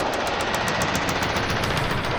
Index of /musicradar/rhythmic-inspiration-samples/110bpm
RI_DelayStack_110-03.wav